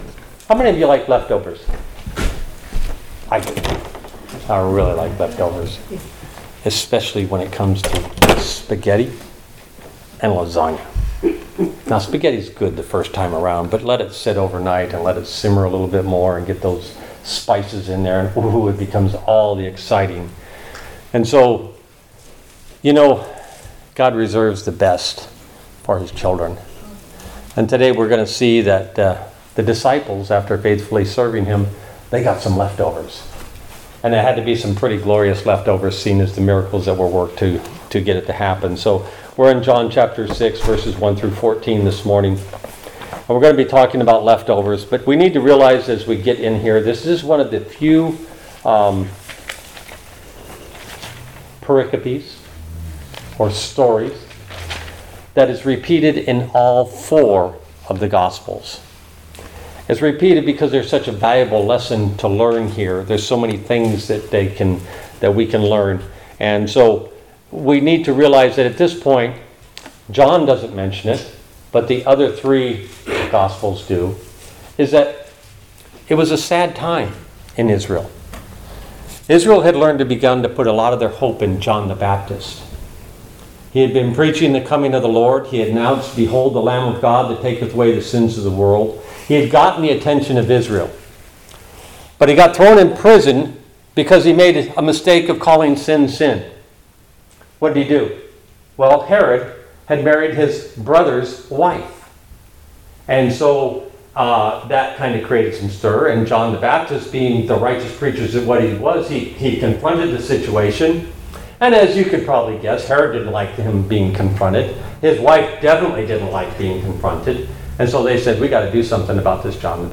All Sermons